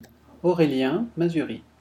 pronunciation.mp3